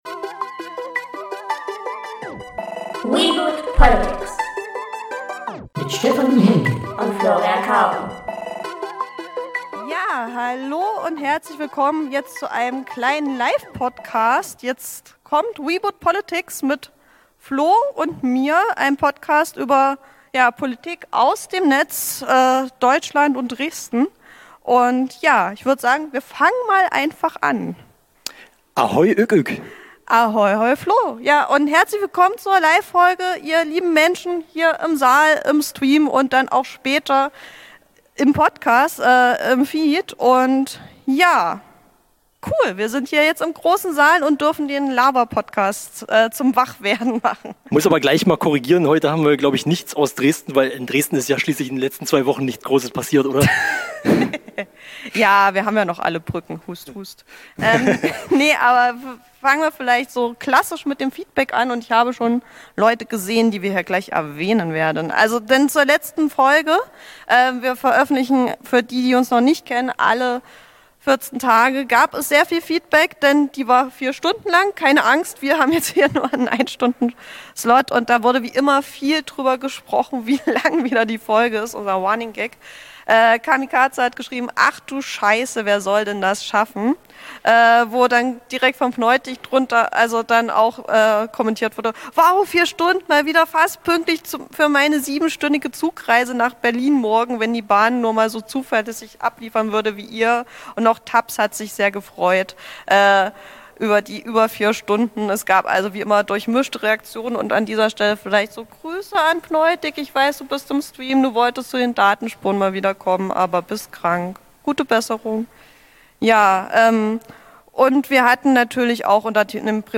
Glaubt nicht den Plakaten - Live von den Datenspuren ~ Reboot Politics Podcast
glaubt-nicht-den-plakaten-live-von-den-datenspuren.mp3